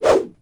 FootSwing9.wav